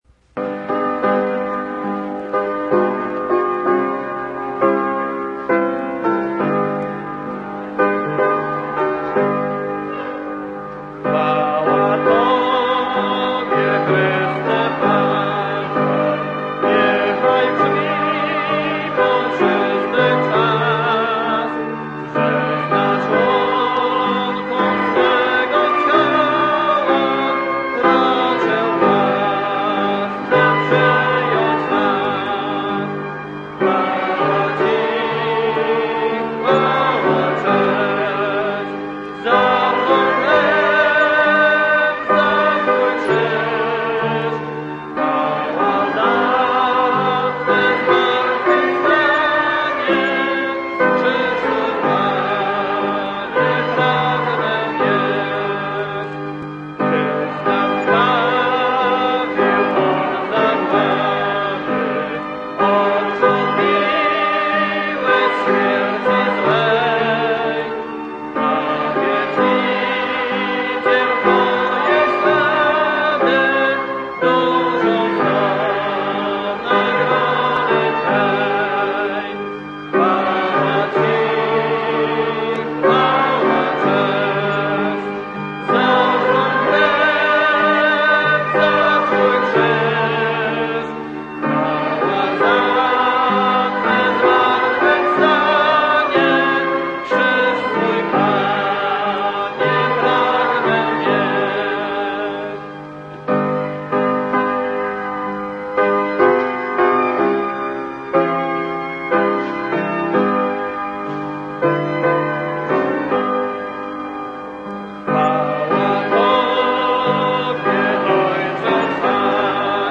Song_Service_-_Music_Program.mp3